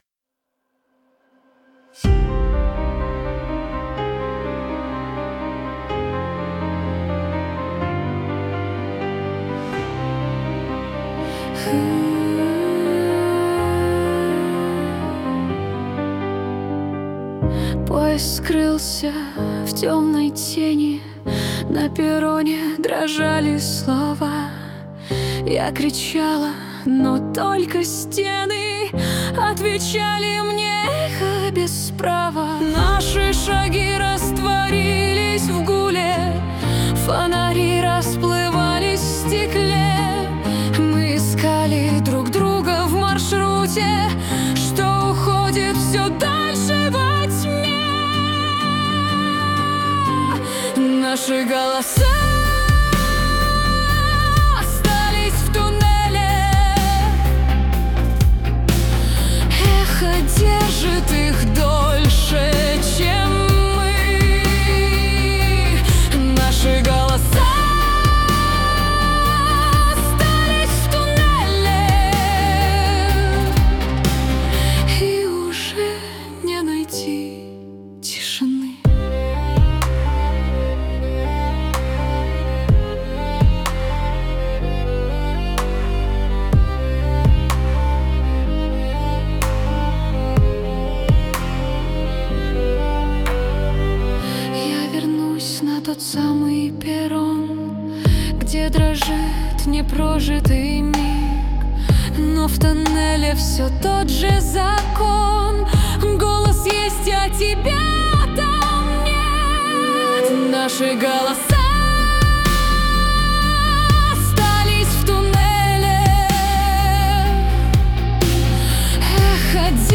• Исполняет: Поставторcкий арт